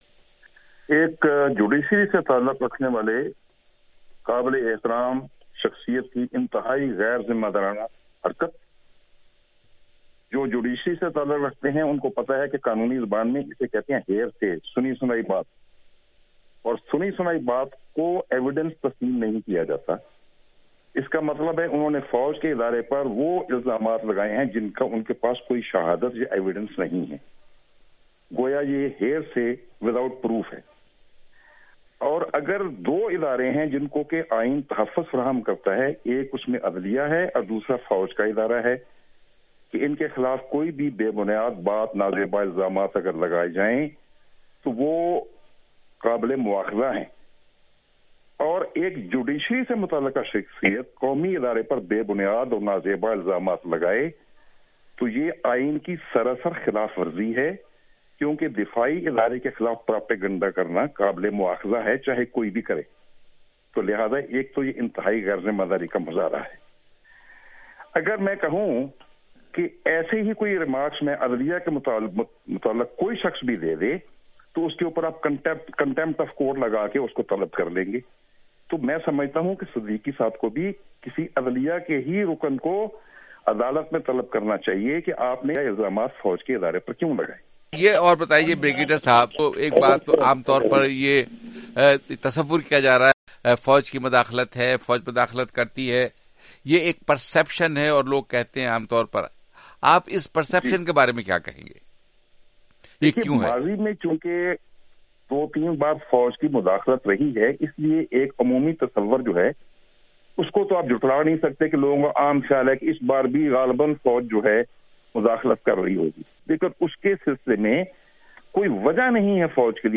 JR discussion- Analysts on Justice Shaukat's remarks